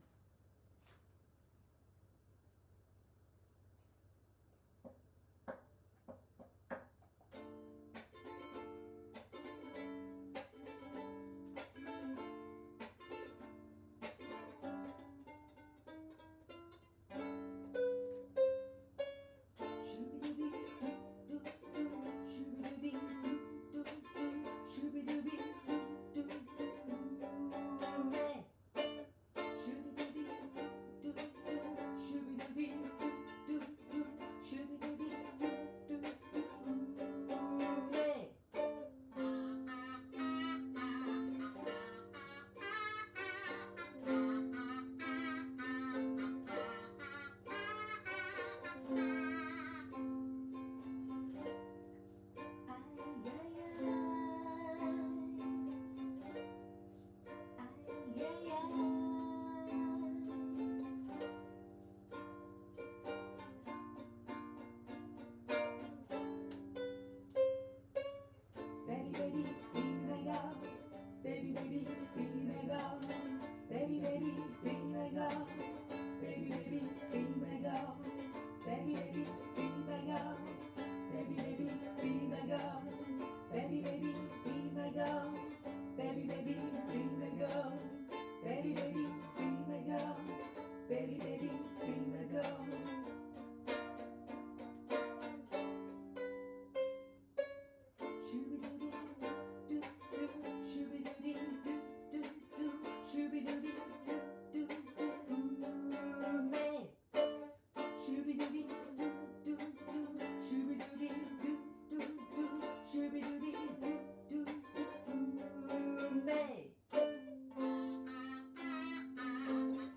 下の段のコーラスが入っています。